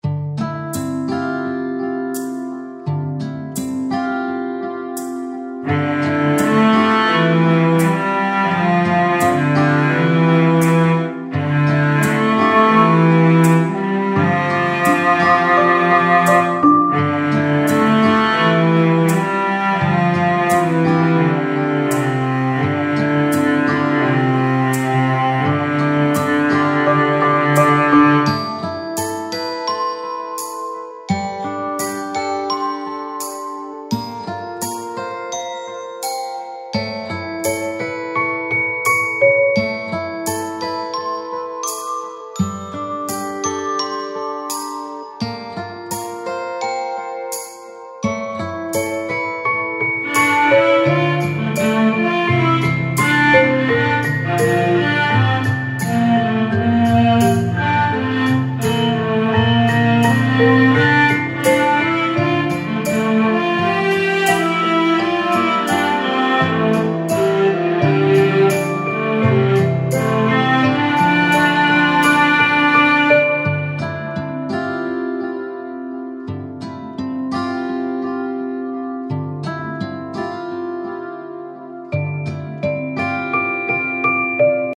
悲しみを強く押し出すのではなく、懐かしさと孤独がゆっくりと滲むような空気感を大切にしています。
• テンポ：ゆったりとしたミドル〜スロー
• 構成：大きな展開を作らず、反復を重視
• 音域：低〜中域中心で、耳に刺さらない設計
リバーブは広がりすぎないよう抑え、「開けた山」ではなく閉ざされた山奥の空間感を意識しています。
メロディは記憶の断片のように輪郭を曖昧にし、はっきり歌わせないことで聴き手それぞれの記憶を重ねられる余白を残しています。
フリーBGM ホラー アンビエント 切ない 癒し ピアノ ミステリー 自然 回想